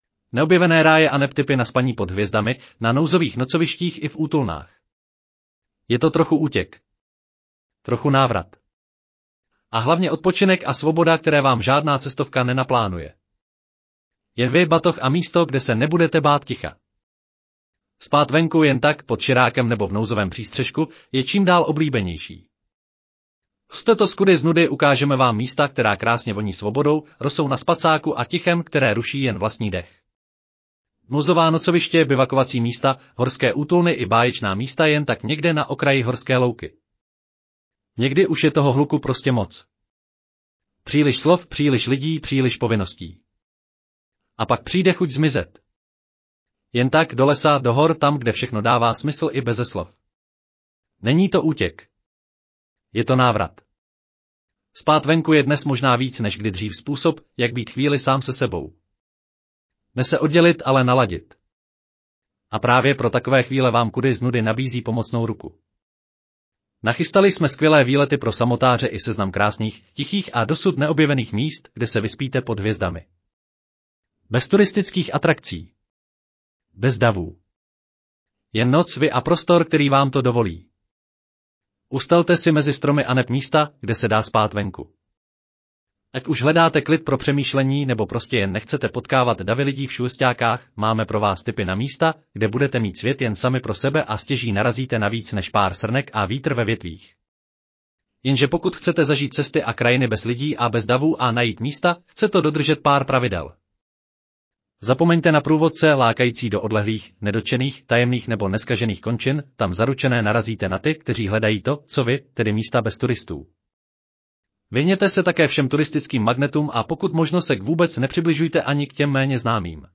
Audio verze článku Neobjevené ráje aneb tipy na spaní pod hvězdami, na nouzových nocovištích i v útulnách